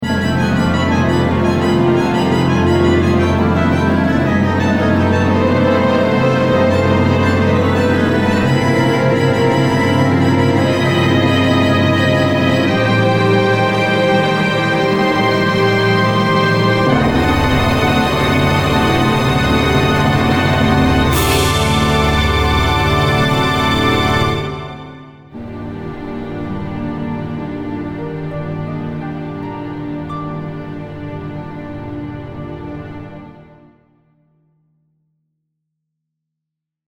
再生する凝縮された高密度なsoundに思わず惹き込まれる--繰り返される一定の波形が王冠を巡る壮絶な歴史を連想させる--狂気渦まく戦闘シーン--火刑に処されるジャンヌの果てしない苦悩と無念さ--天国に召されていく魂...祈り..